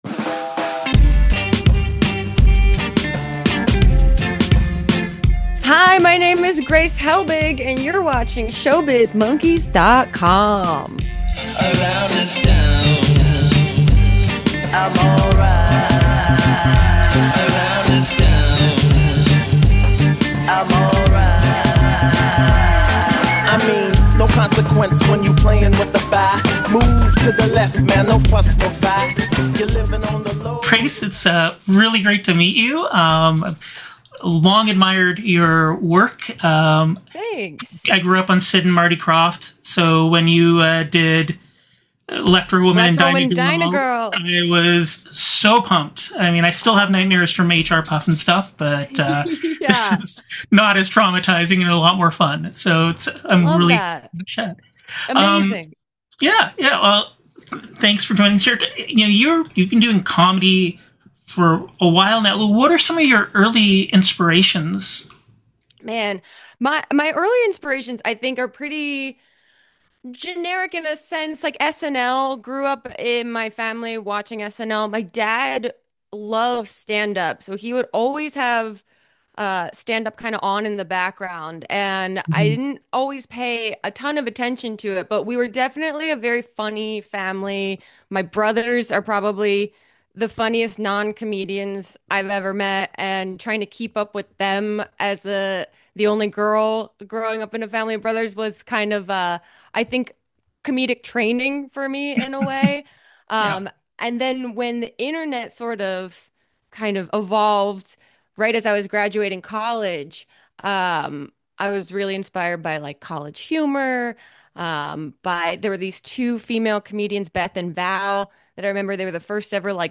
JFL Vancouver Interview: Comedian and YouTuber Grace Helbig She talks about improv at PIT in New York, her start on YouTube, and using her platform to help with healing after her cancer diagnosis